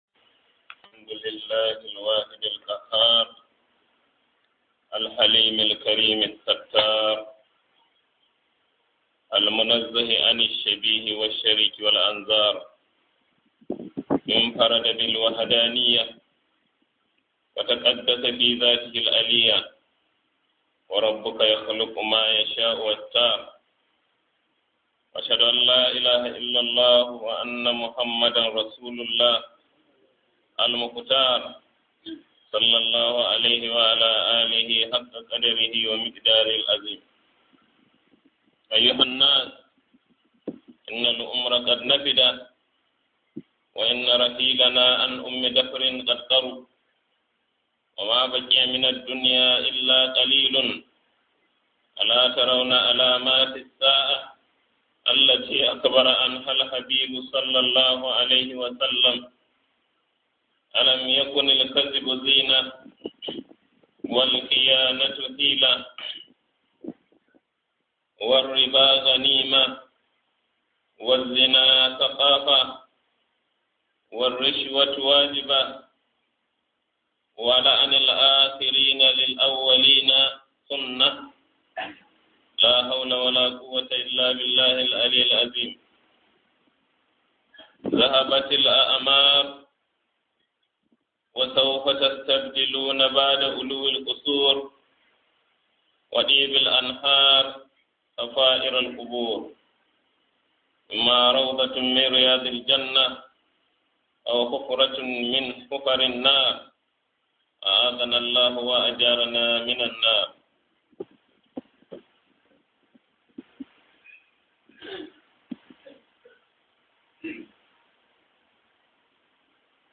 KHUDBAH JUMA-A